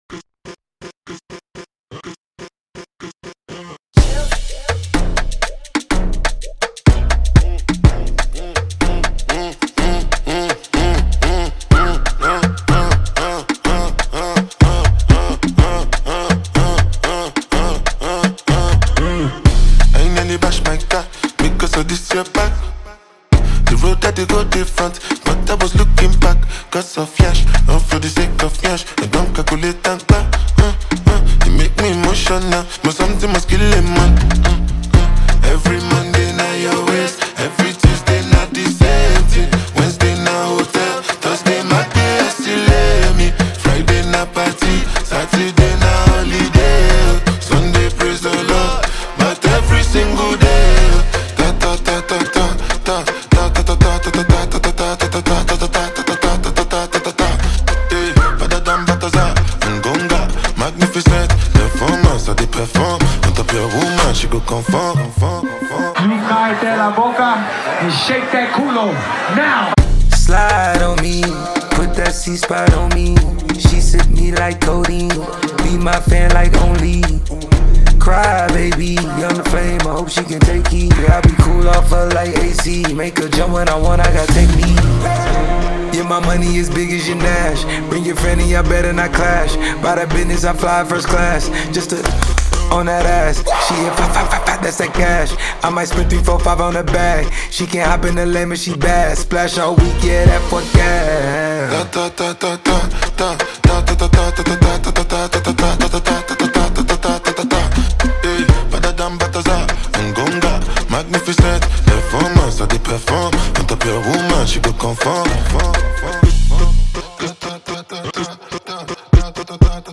Afro-fusion
hypnotic trap energy